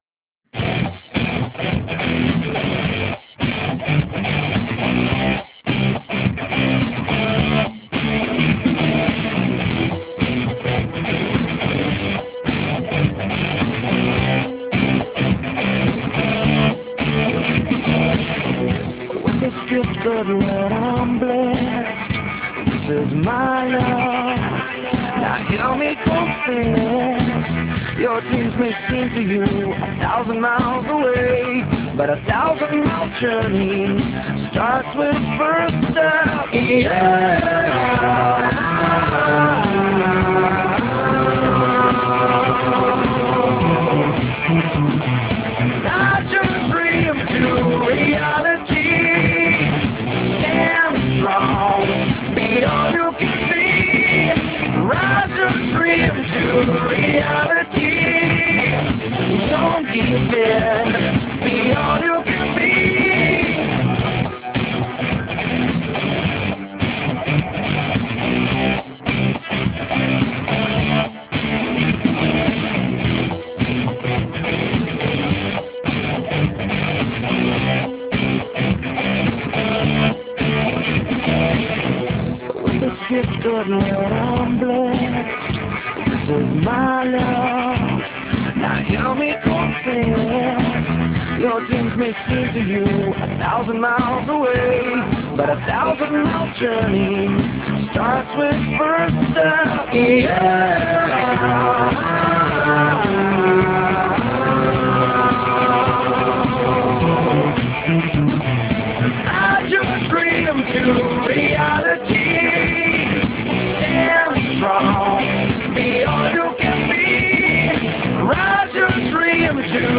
4. Jednu pjesmu s nekakvog CD-a ili MP3 datoteke
wma] Low bitrate voice 6.5kbps